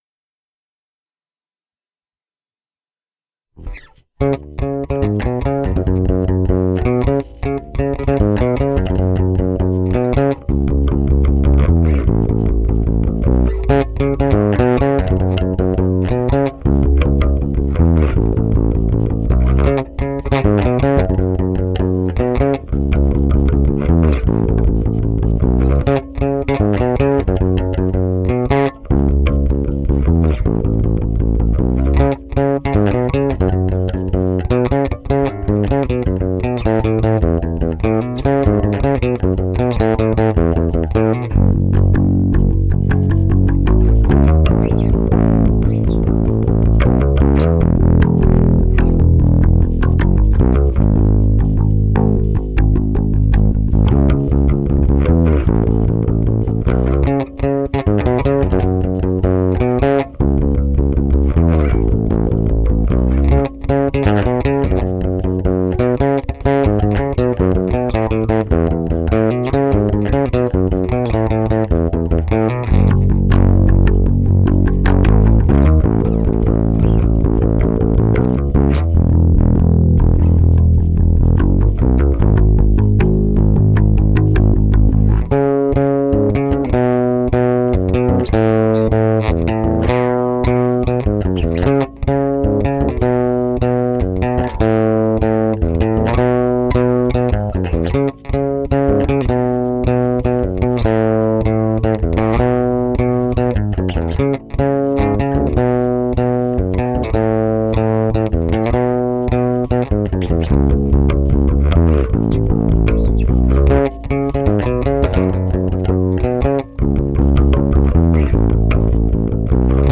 (bass only)